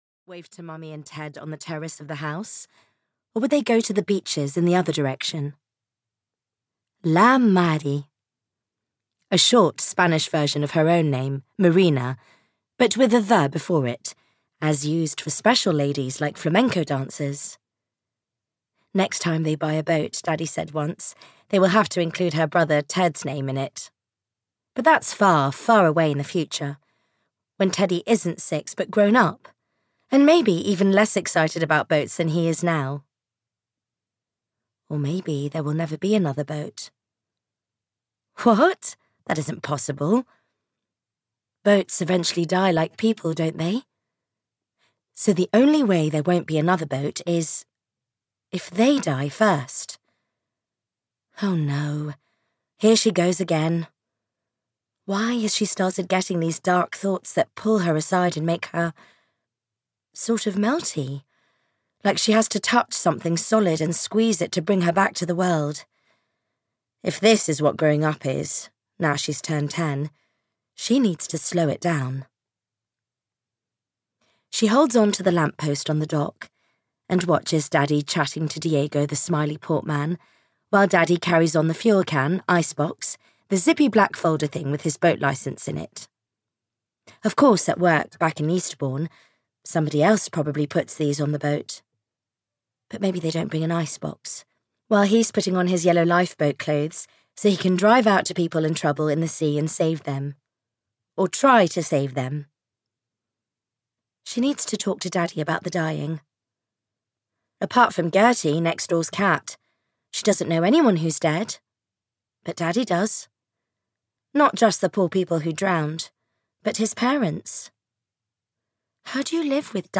The Spanish Cove (EN) audiokniha
Ukázka z knihy